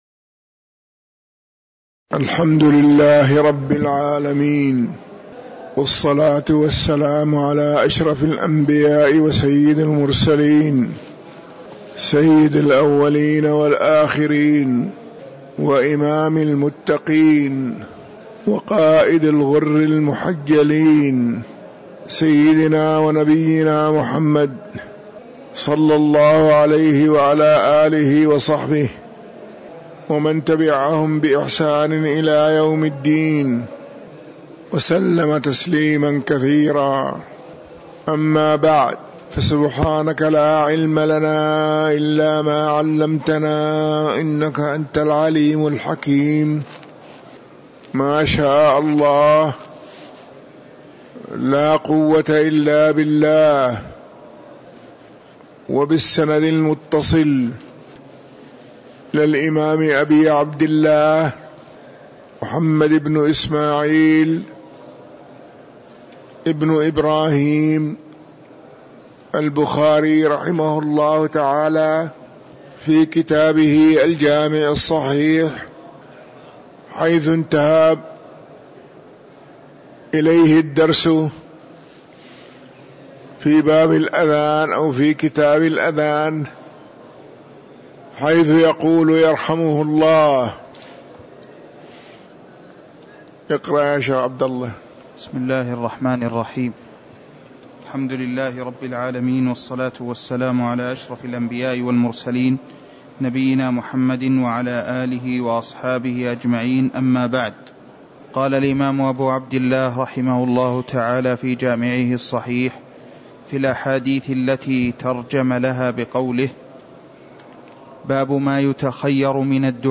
تاريخ النشر ٢٥ ربيع الثاني ١٤٤٣ هـ المكان: المسجد النبوي الشيخ